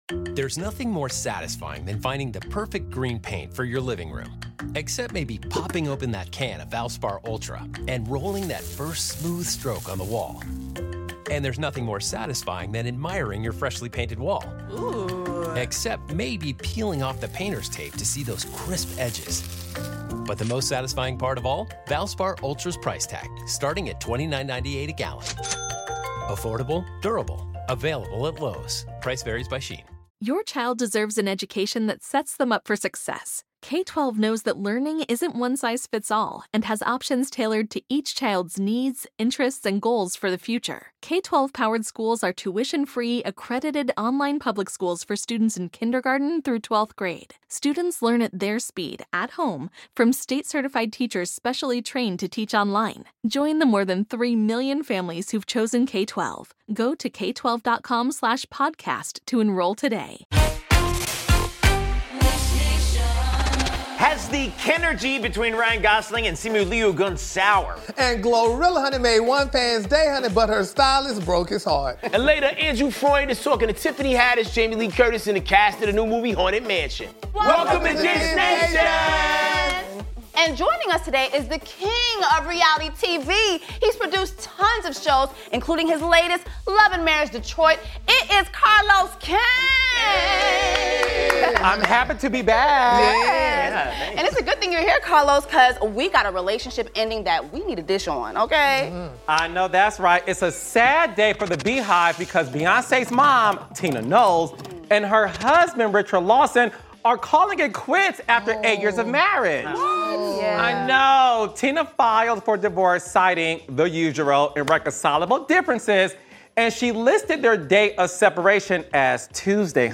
Last but not least, we dish with 'Haunted Mansion' cast, Jamie Lee Curtis, Tiffany Haddish, Rosario Dawson, and Lakeith Stanfield.